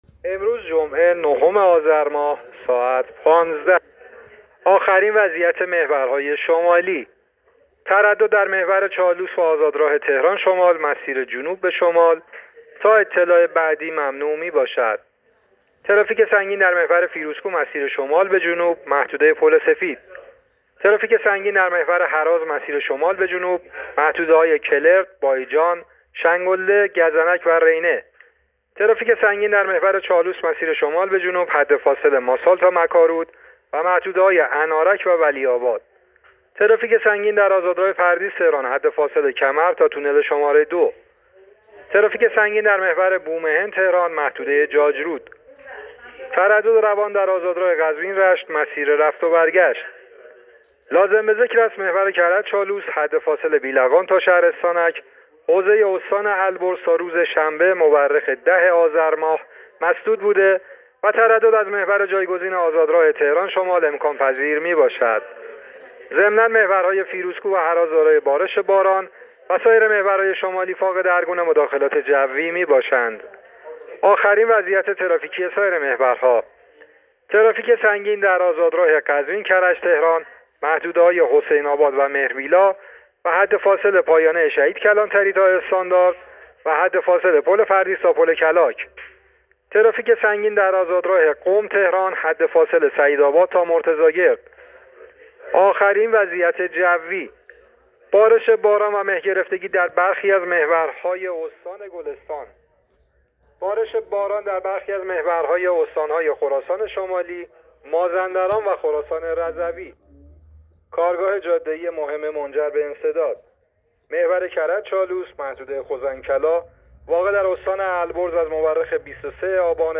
گزارش رادیو اینترنتی از آخرین وضعیت ترافیکی جاده‌ها تا ساعت ۱۵ نهم آذر